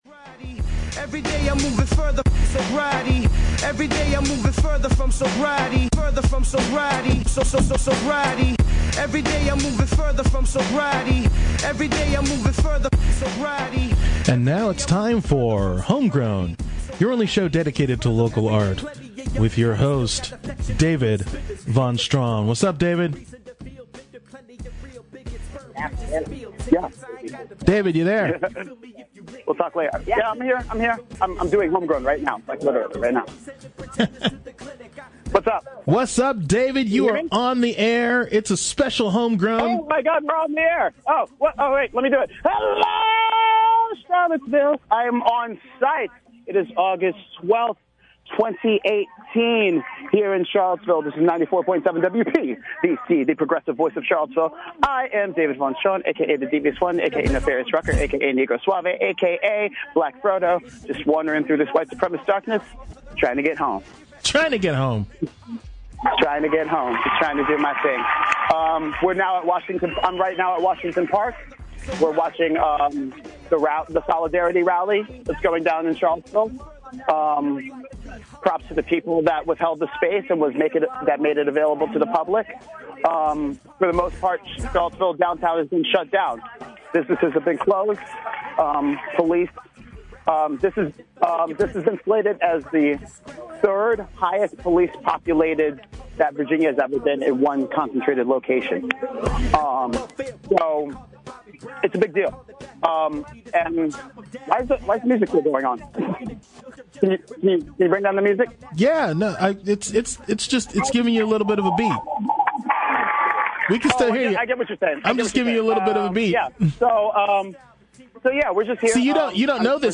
He talks about where he’s at, where we’re at, and interviews people on the ground.